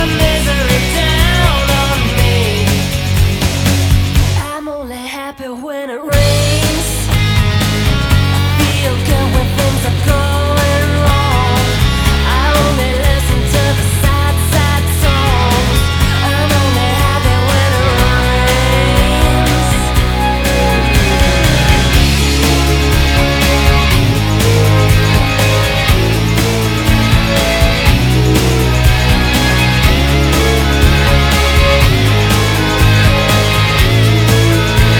Жанр: Рок
Rock